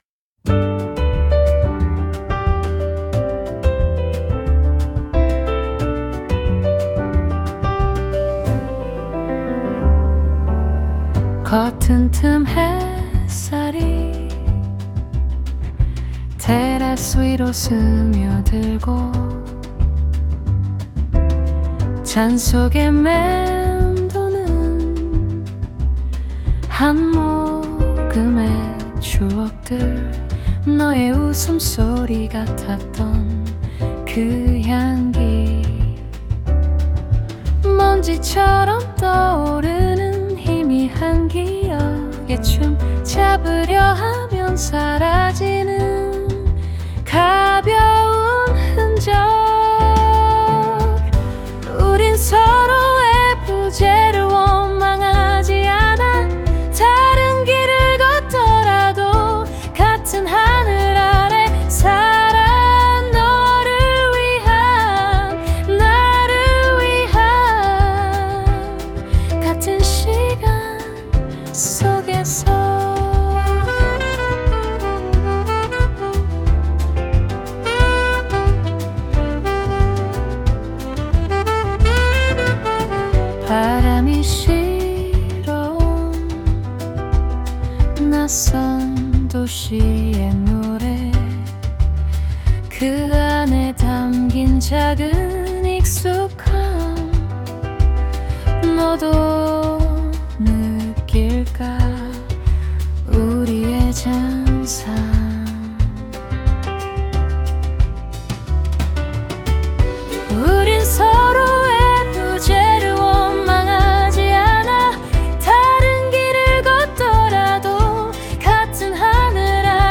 다운로드 설정 정보 Scene (장면) cafe_terrace Topic (주제) 커튼 틈 햇살, 먼지처럼 떠오르는 추억, 조용한 미소. 서로의 부재를 원망하지 않고, 같은 시간 속에서 각자 살아가길 빌어주는 마음 Suno 생성 가이드 (참고) Style of Music Bossa Nova, Jazz Piano, Lo-Fi, Female Vocals, Soft Voice Lyrics Structure [Meta] Language: Korean Topic: 커튼 틈 햇살, 먼지처럼 떠오르는 추억, 조용한 미소.